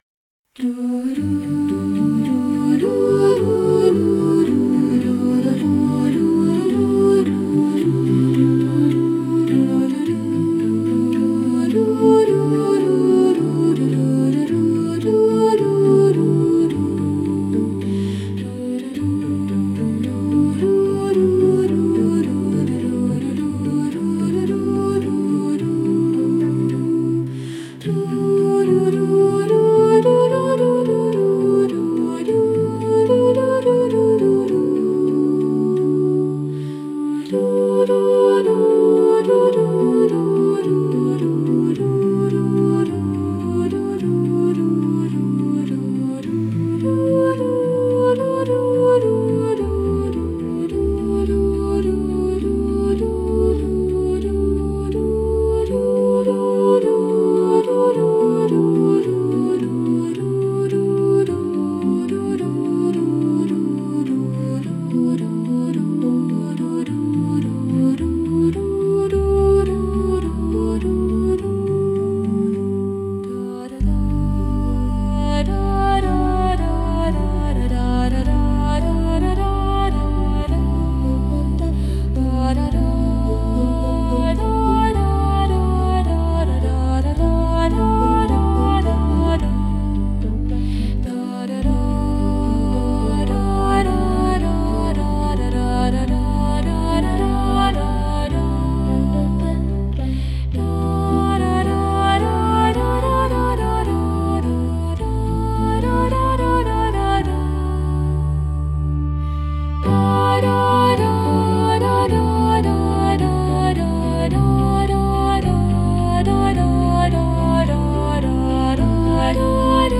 04-hymn-ii-lo-chor-wokaliza.mp3